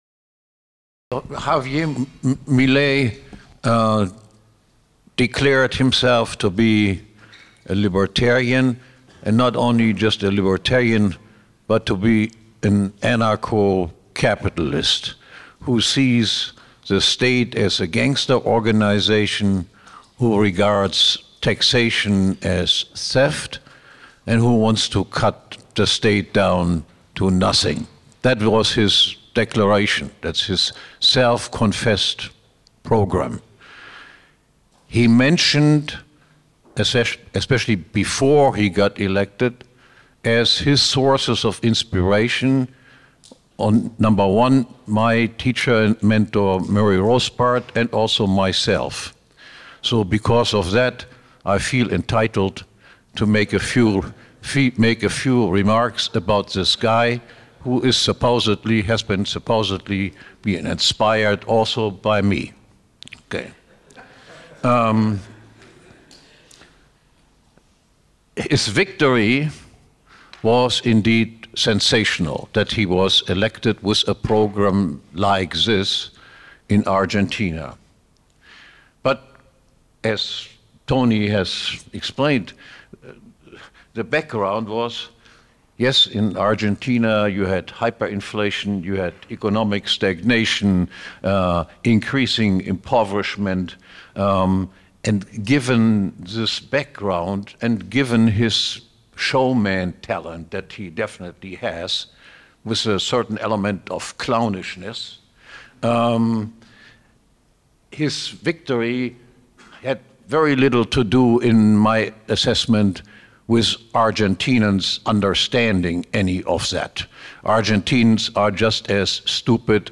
This talk is from the 18th annual 2024 Annual Meeting of the PFS (Sept. 19–24, 2024, Bodrum, Turkey).